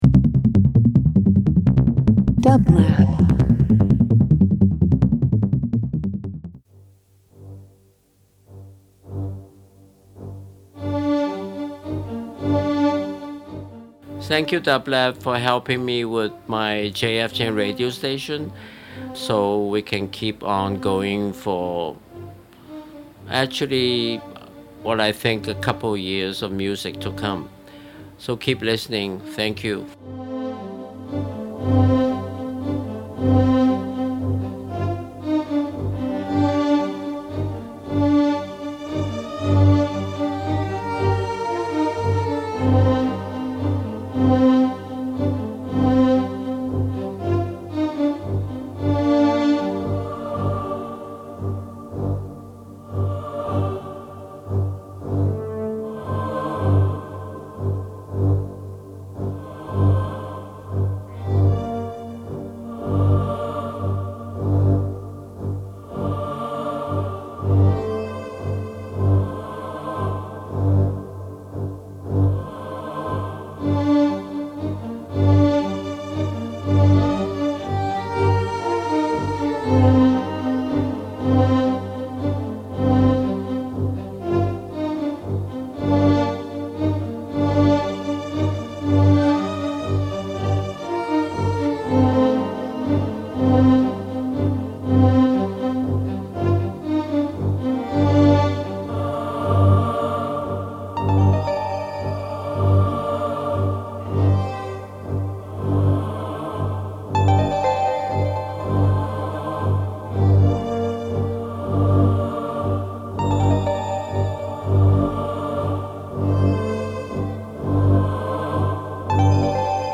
Brazilian Hip Hop Rock Soundtracks